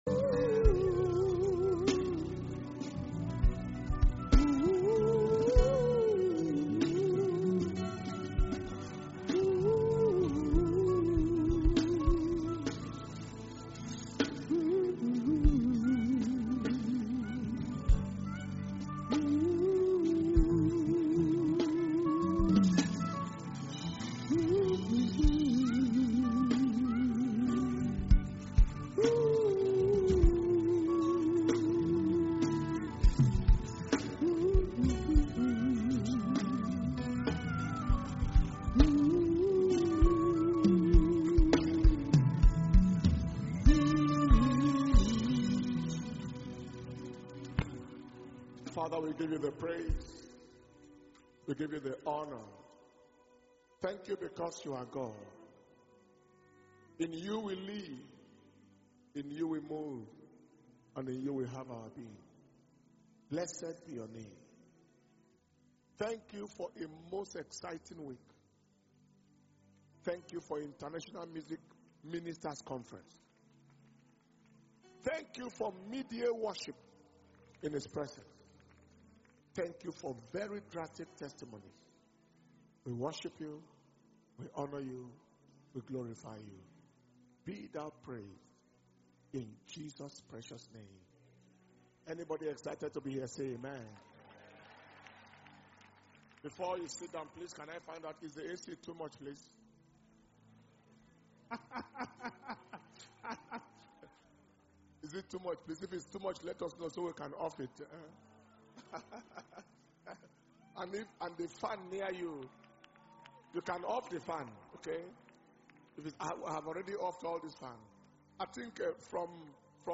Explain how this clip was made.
August 2021 Blessing Sunday Service Message - Sunday, 1st August 2021